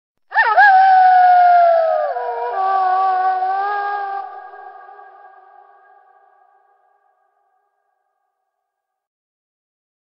دانلود صدای زوزه گرگ 3 از ساعد نیوز با لینک مستقیم و کیفیت بالا
جلوه های صوتی